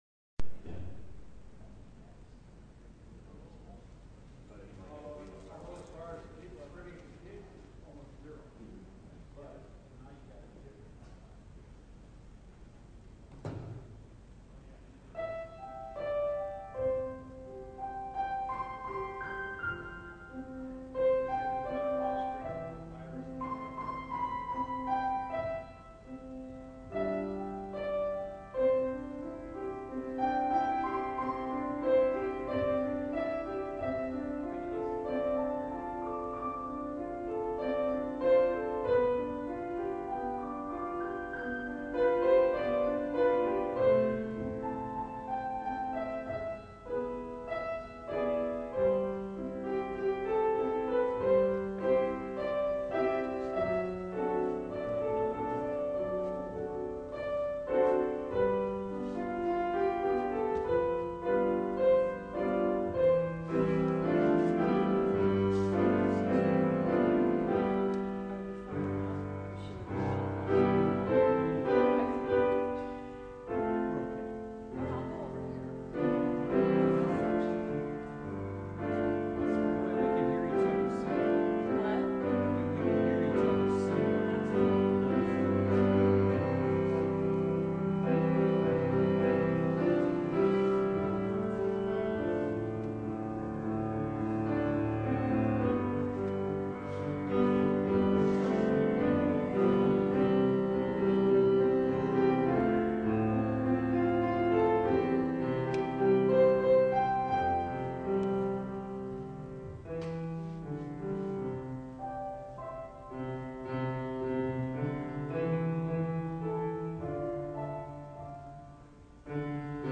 Single Sermons Passage: John 4:16-26 Service Type: Morning Bible Text